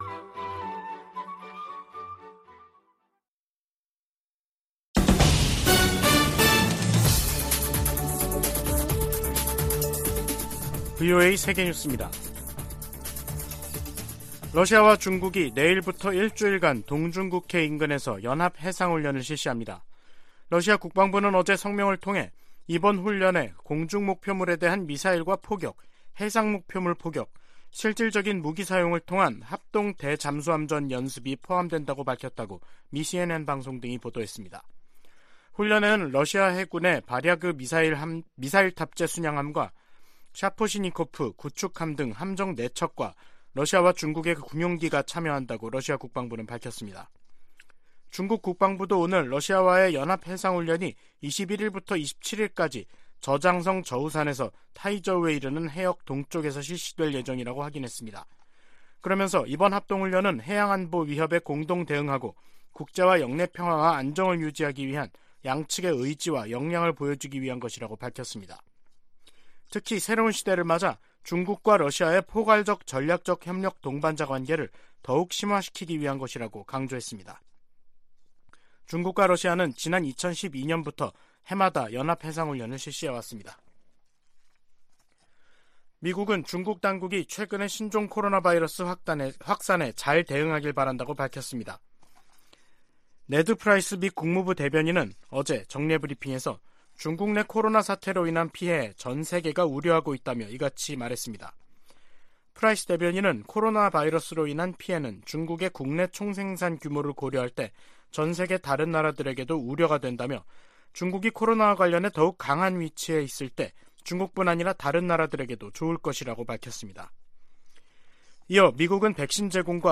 VOA 한국어 간판 뉴스 프로그램 '뉴스 투데이', 2022년 12월 20일 2부 방송입니다. 미 국무부는 북한이 정찰위성 시험이라고 주장한 최근 미사일 발사가 전 세계를 위협한다며, 외교로 문제를 해결하자고 촉구했습니다. 유엔은 북한의 최근 탄도미사일 발사와 관련해 한반도 긴장 고조 상황을 매우 우려한다며 북한에 즉각적인 대화 재개를 촉구했습니다.